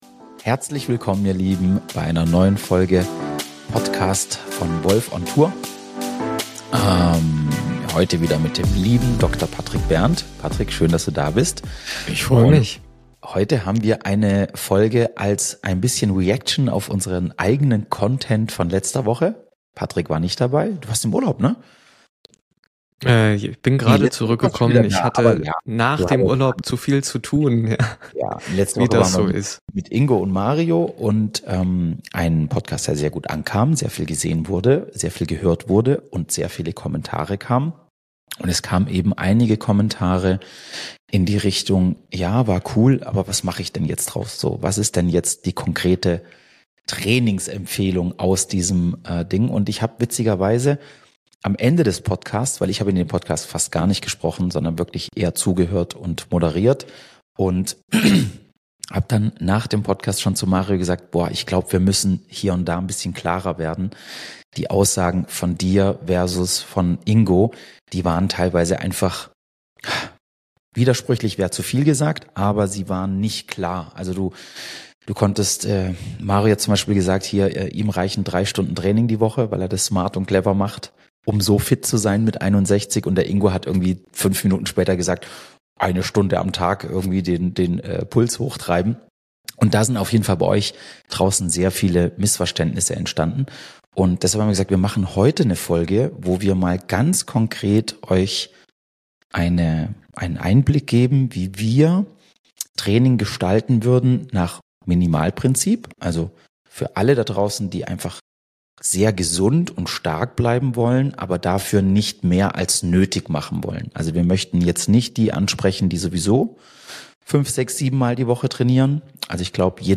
Heute gibt’s klare Antworten: Wir sprechen über Training, Regeneration, Mindset - und was wirklich hinter Fortschritt steckt. Ein ehrliches Gespräch über Körper, Geist und die Kunst, Balance statt Burnout zu finden.